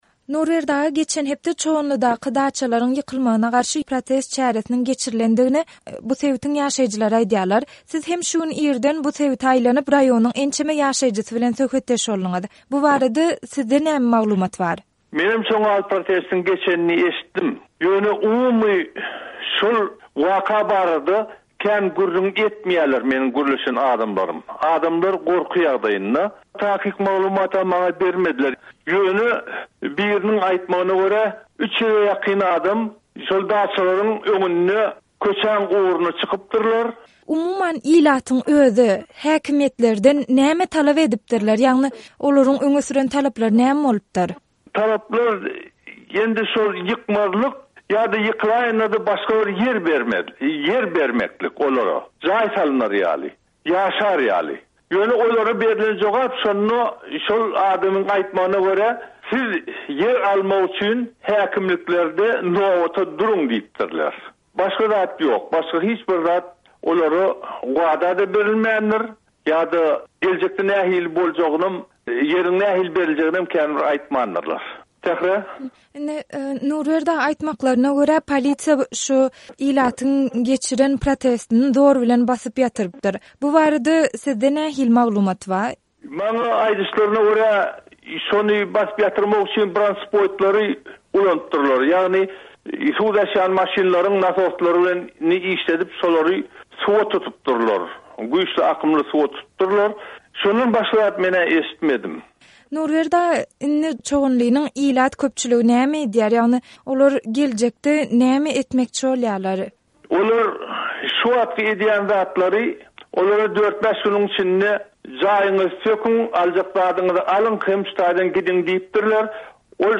by Azatlyk Radiosy